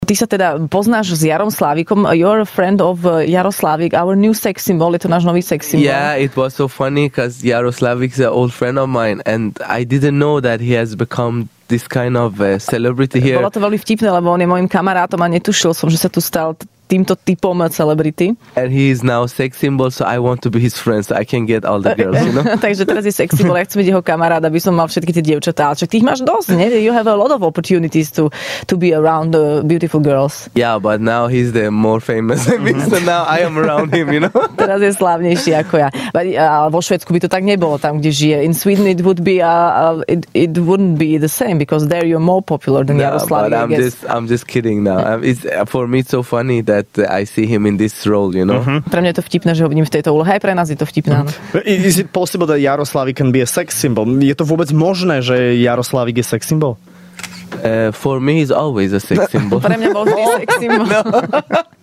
Svetový spevák prišiel do Rannej šou a hovoril aj jeho priateľstve s Jarom Slávikom.